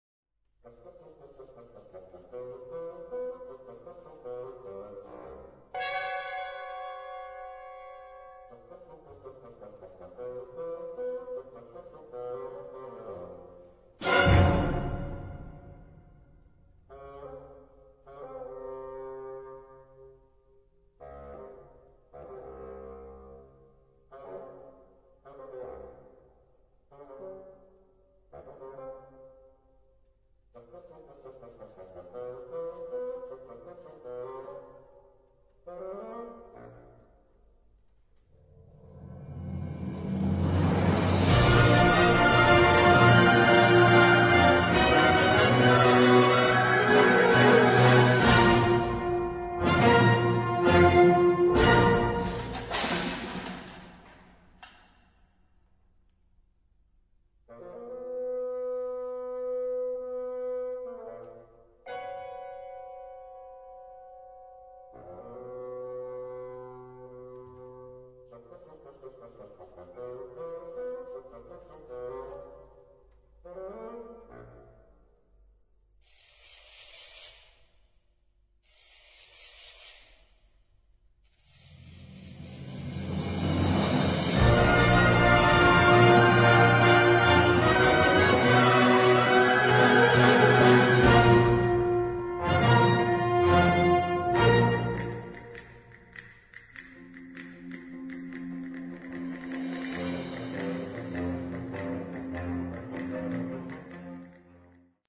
Partitions pour orchestre d'harmonie.